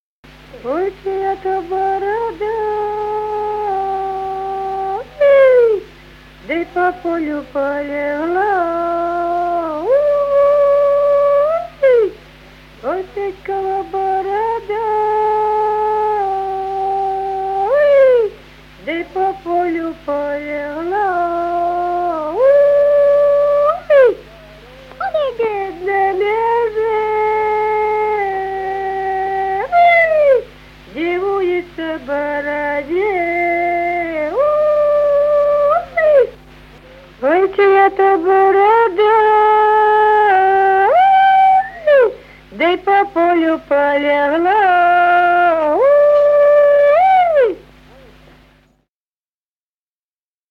Народные песни Стародубского района «Ой, чия та борода», жнивная.
с. Курковичи.